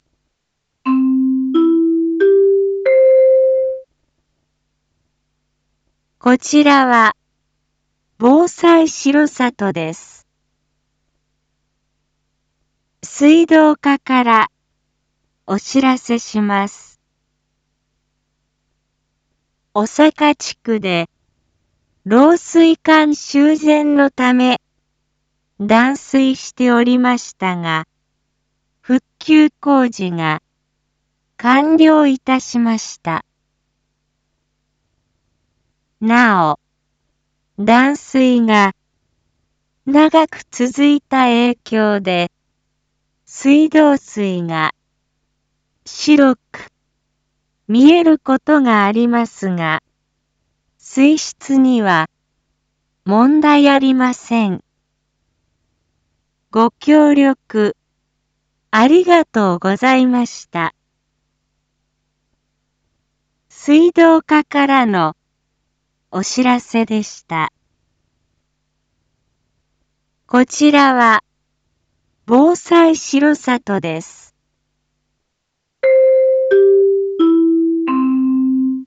一般放送情報
Back Home 一般放送情報 音声放送 再生 一般放送情報 登録日時：2024-01-09 15:56:20 タイトル：R6.1.9 小坂地区断水復旧のお知らせ（地区限定） インフォメーション：こちらは、防災しろさとです。